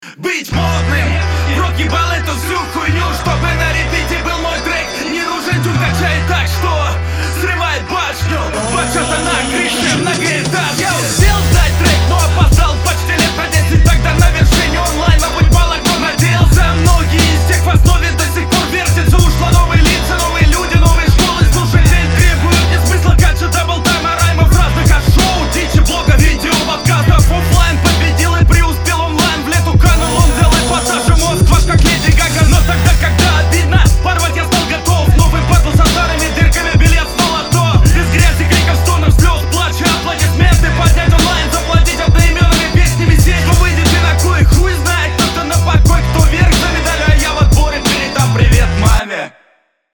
Довольно ярко и экспрессивно подаёшь, жаль взаимодействие с битом не идеально и не все строки логичны и хорошо зарифмованы. Местами излишне растягиваешь слова. К концу словно подустал.